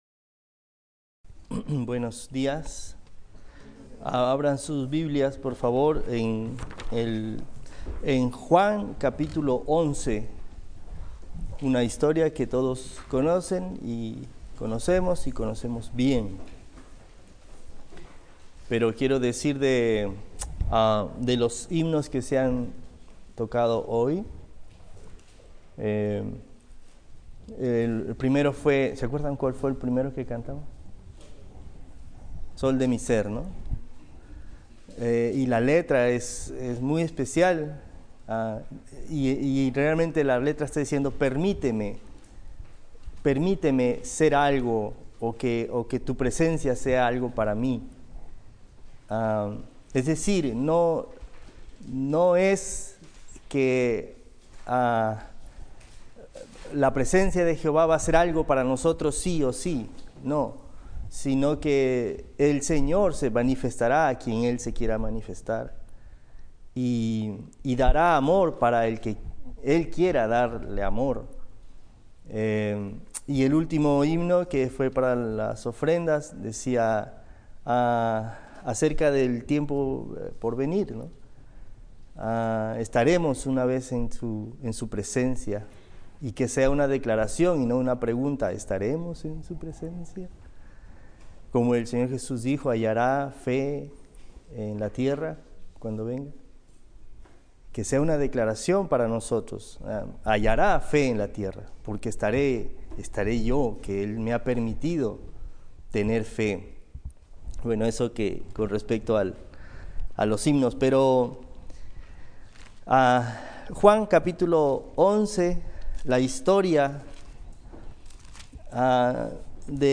28 de febrero del 2025 Capilla